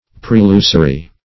Prelusory \Pre*lu"so*ry\, a.
prelusory.mp3